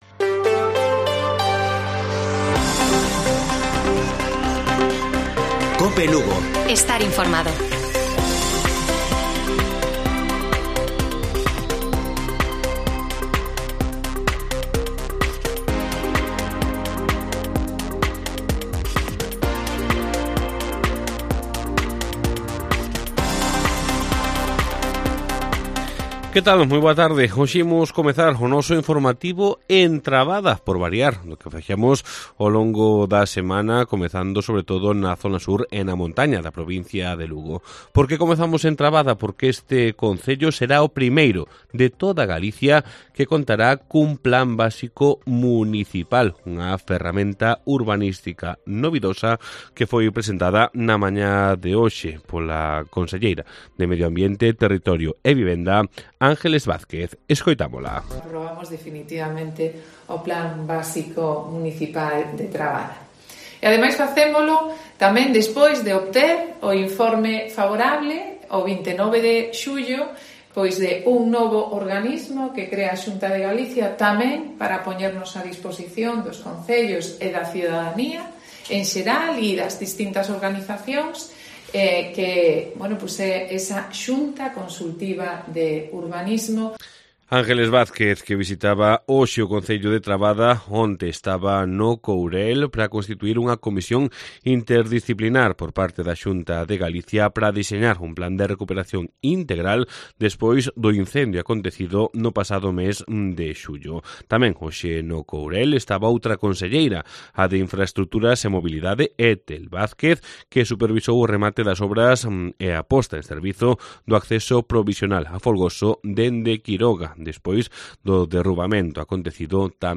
Informativo Mediodía de Cope Lugo. 5 DE AGOSTO. 14:20 horas